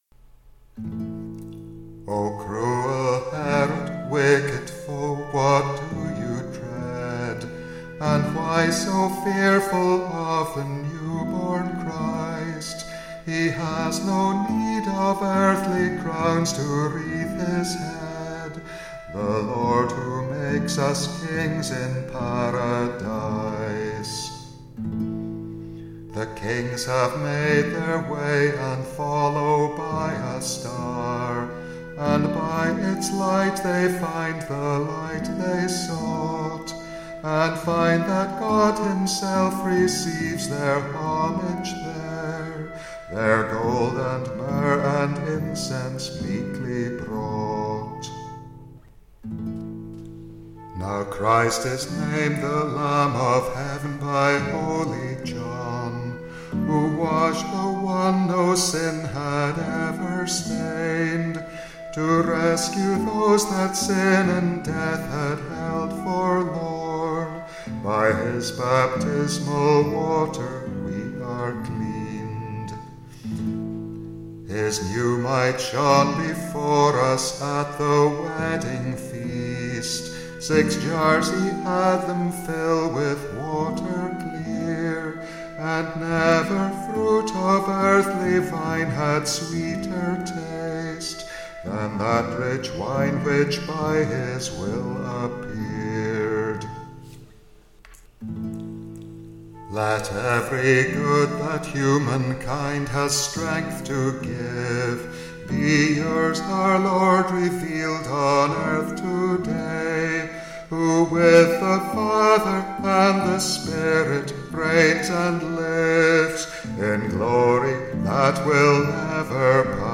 The words are as close as I could get to the sense of an ancient Latin hymn, Hostis Herodes: the tune is adapted from the plainchant used for it.